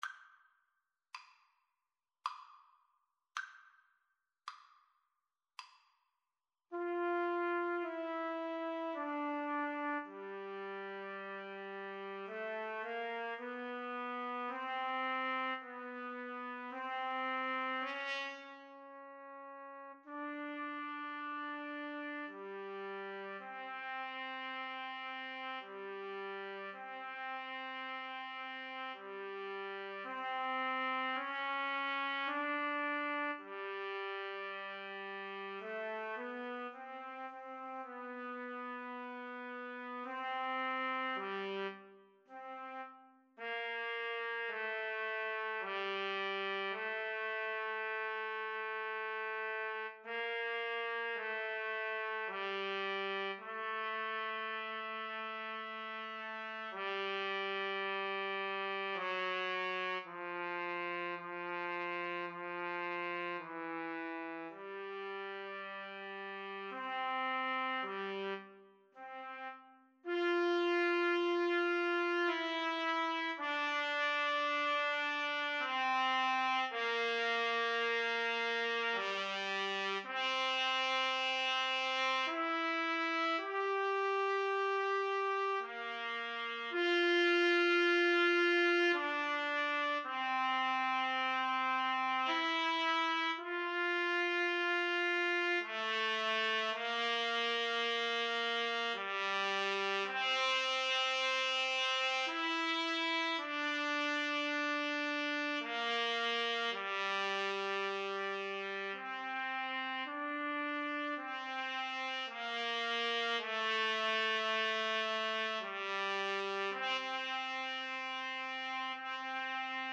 3/4 (View more 3/4 Music)
Andante sostenuto ( = 54)
Classical (View more Classical Trumpet Duet Music)